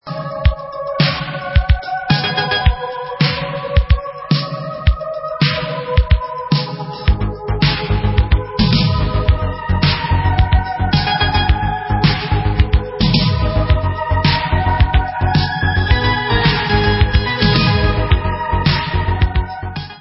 sledovat novinky v oddělení Disco